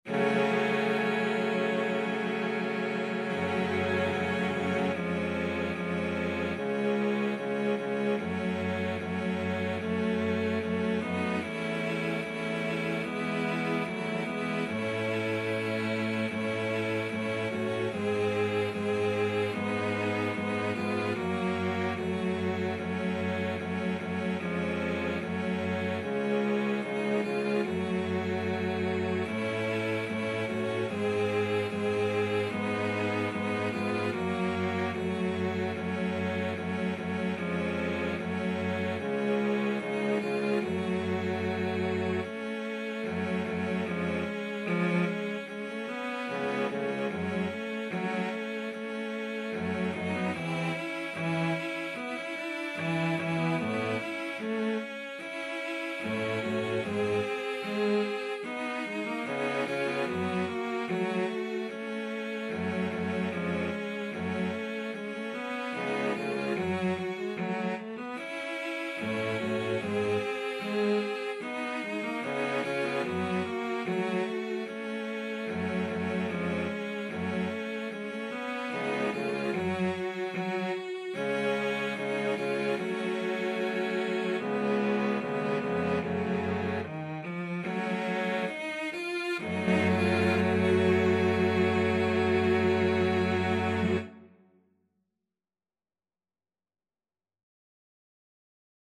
2/4 (View more 2/4 Music)
Allegretto = c. 74
Cello Quartet  (View more Intermediate Cello Quartet Music)
Classical (View more Classical Cello Quartet Music)